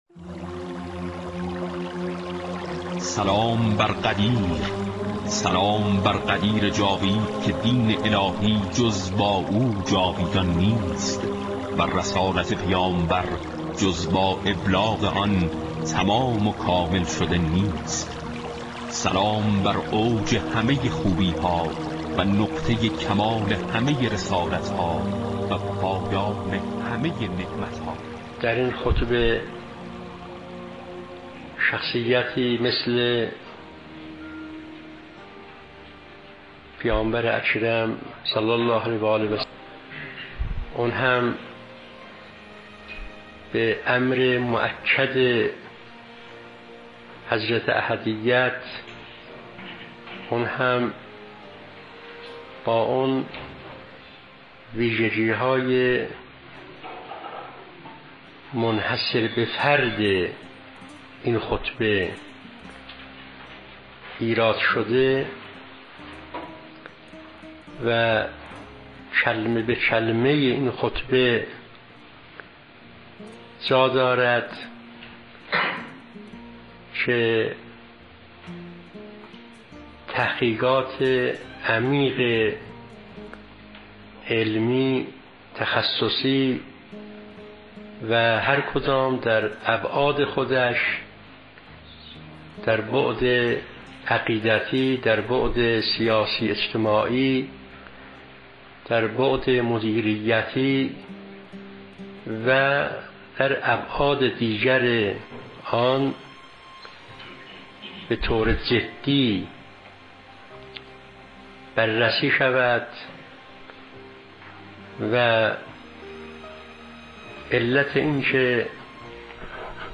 ◼ سخنرانی